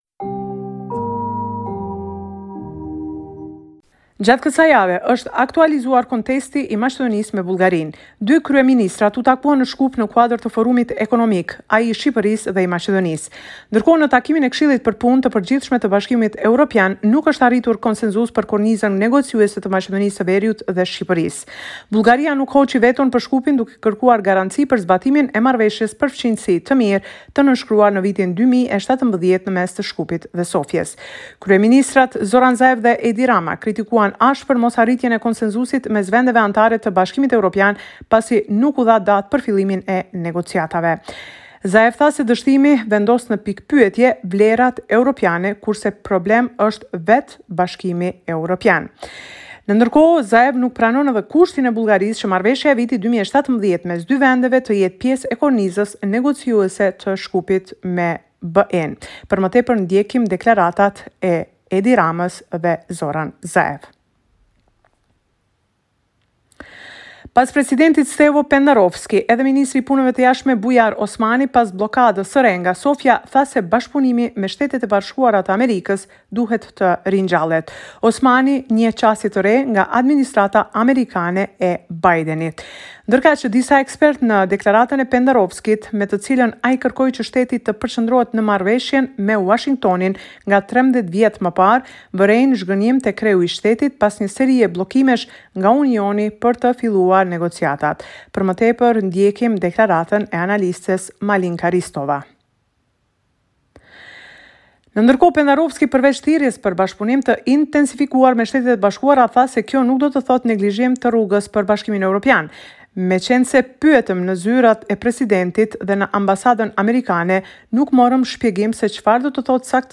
Raporti me te rejat me te fundit nga Maqedonia e Veriut.